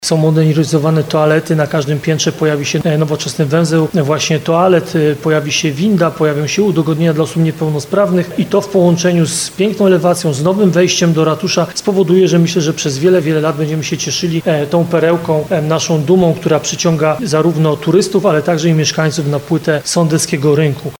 – mówił prezydent Nowego Sącza Ludomir Handzel.